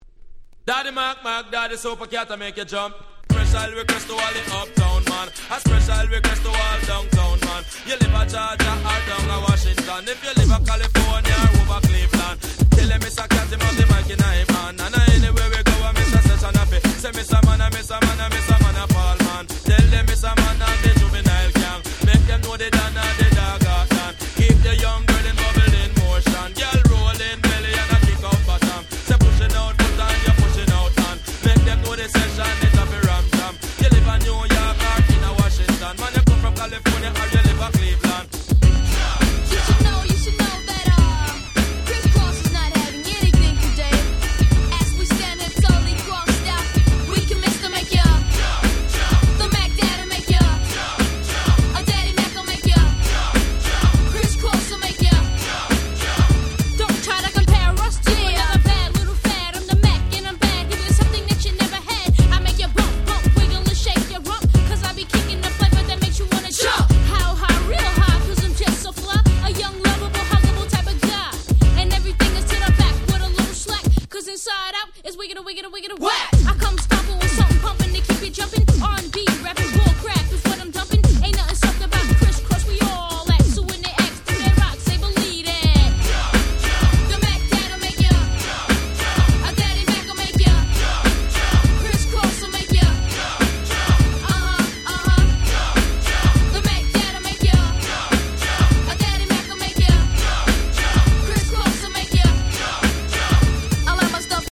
93' Super Hit Hip Hop !!